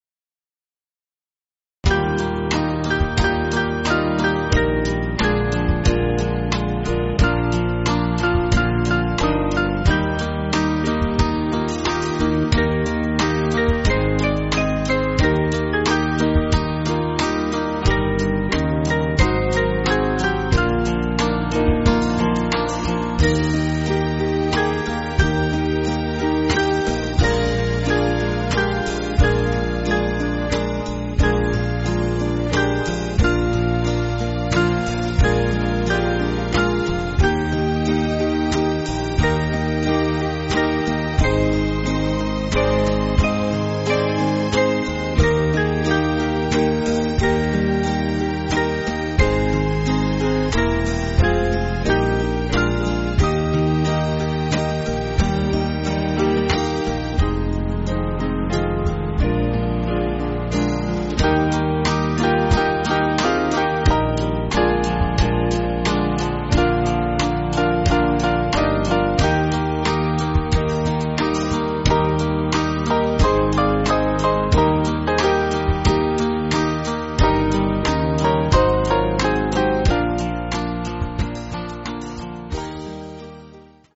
8.7.8.7.D
Small Band
(CM)   4/Eb